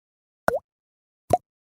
Звуки нажатия кнопки
Эффект кнопки